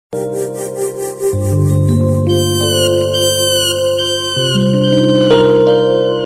دانلود صدای هشدار موبایل 56 از ساعد نیوز با لینک مستقیم و کیفیت بالا
جلوه های صوتی